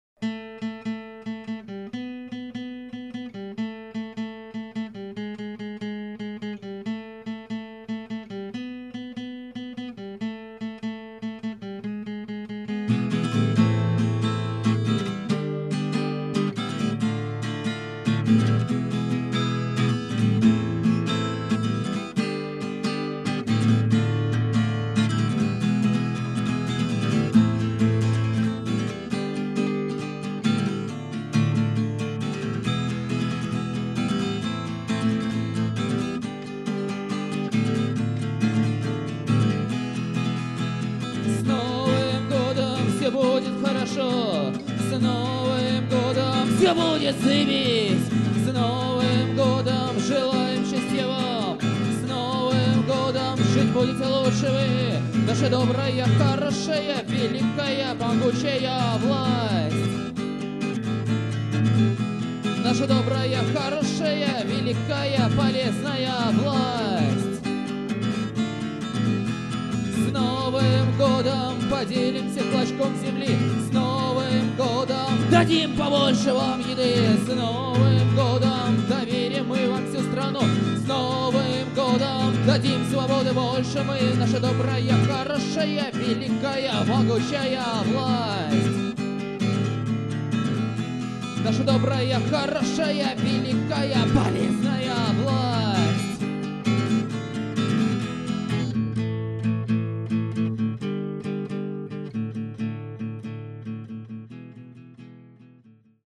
Панк-рок, местами с элементами психоделии.